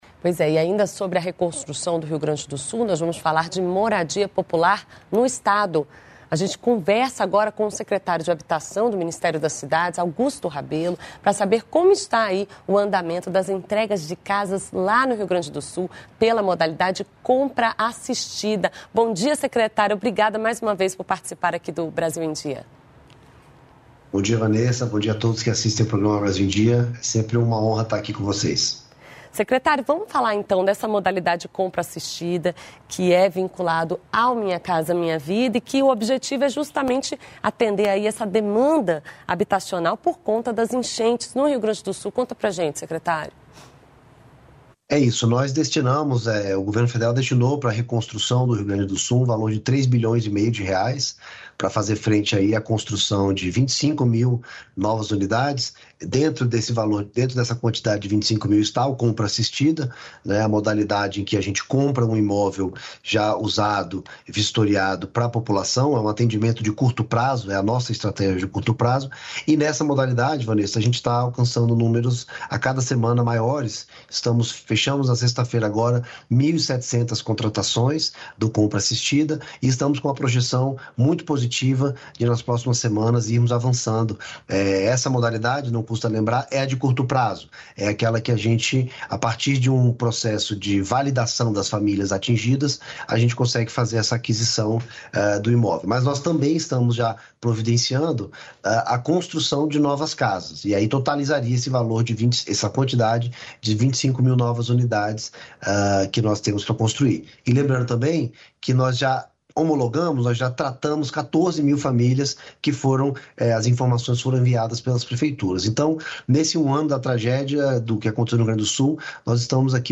Brasil em Dia - Entrevista
Para saber como está o andamento das entregas de casas no Rio de Grande do Sul pelo Compra Assistida, programa vinculado ao Minha Casa Minha Vida, o Brasil em Dia conversa com o secretário de Habitação do Ministério das Cidades, Augusto Rabelo.